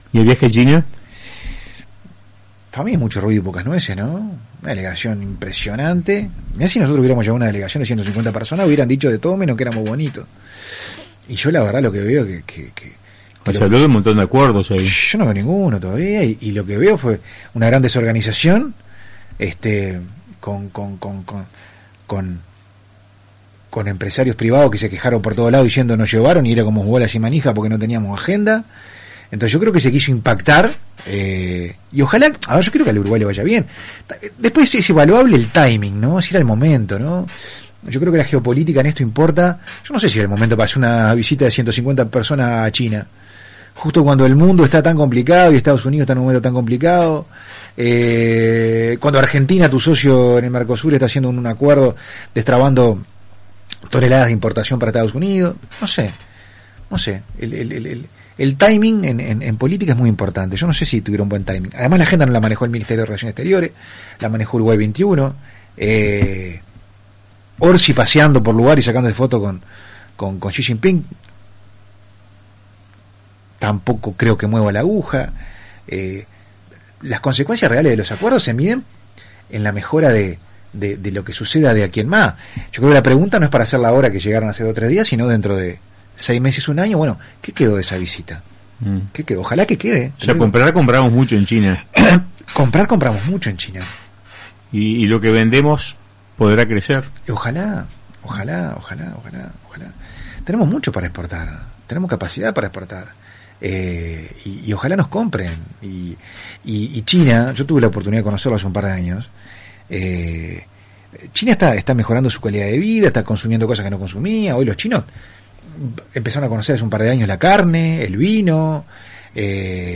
Entrevistado en el programa Radio con Todos de RBC, el legislador expresó: